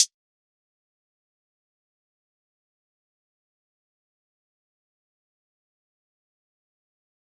OZ - HH 4.wav